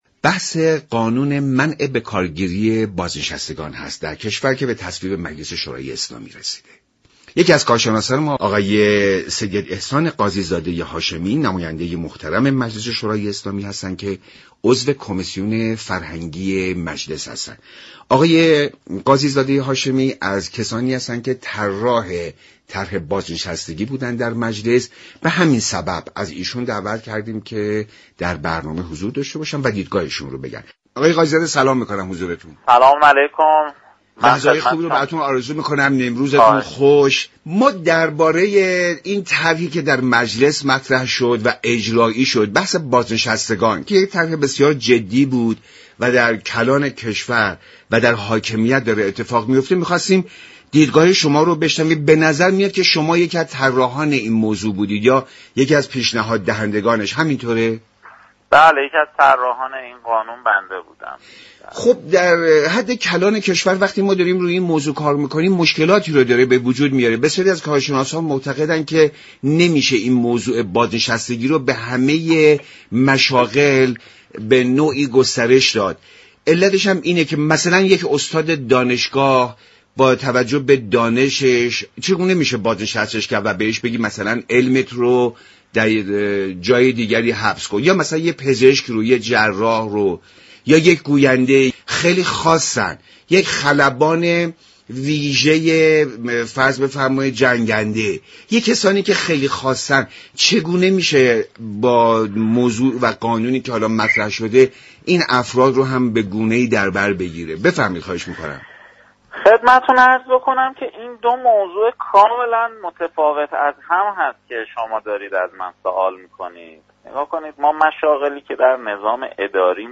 گفت و گو با كارشناسان درباره قانون منع بكارگیری بازنشستگان در برنامه میزبان